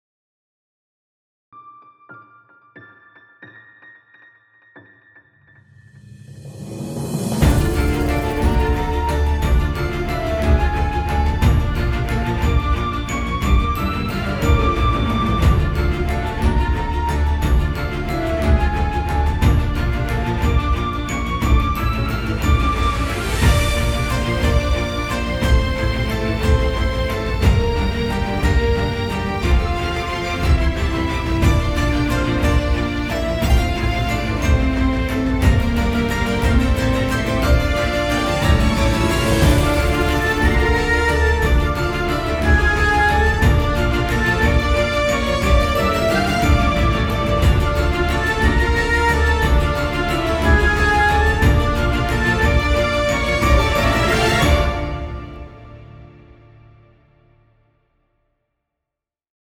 Composition Feedback - Orchestral and Large Ensemble
Please take a listen tot his short piece I composed & programmed yesterday and give me your honest feedback. Its for a story I thought up.